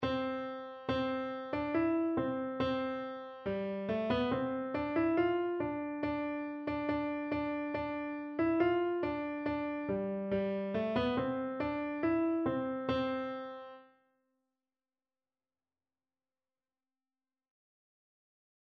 No parts available for this pieces as it is for solo piano.
Traditional Music of unknown author.
4/4 (View more 4/4 Music)
Very fast =140